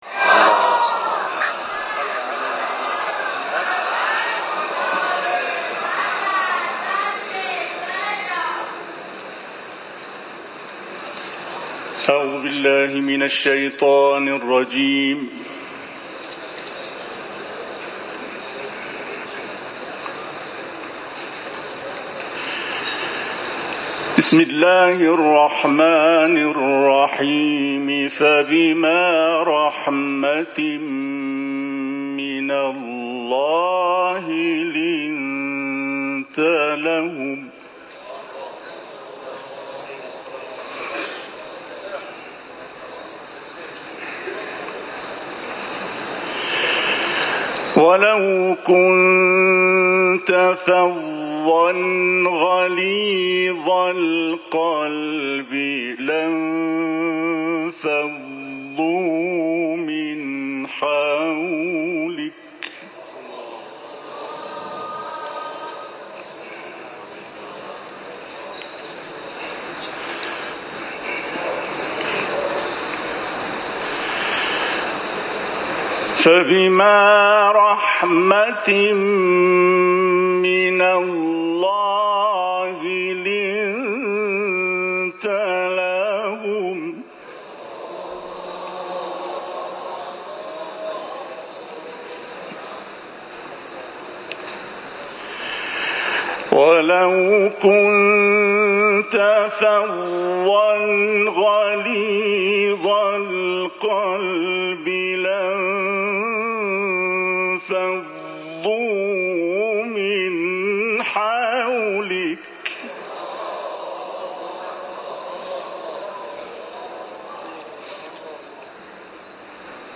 تلاوت
قاری ممتاز کشورمان، آیاتی از سوره مبارکه آل‌عمران را تلاوت کرد.
در دیدار جامعه قرآنی با رهبر معظم انقلاب